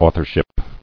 [au·thor·ship]